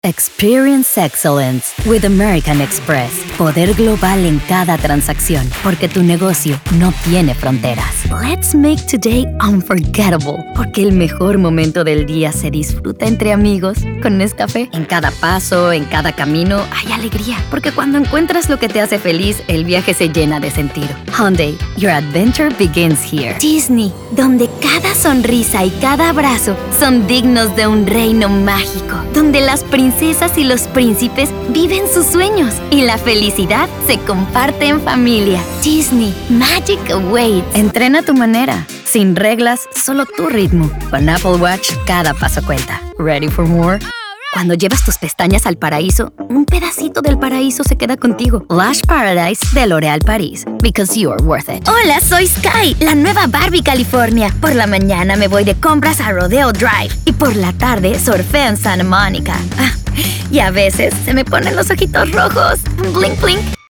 Commercial Version 2
Mexican